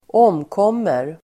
Ladda ner uttalet
omkomma verb, die , be killed Grammatikkommentar: A & Uttal: [²'åm:kåm:er] Böjningar: omkom, omkommit, omkomma, omkommer Synonymer: avlida, förolyckas, stupa Definition: dö genom olyckshändelse, förolyckas (perish)
omkommer.mp3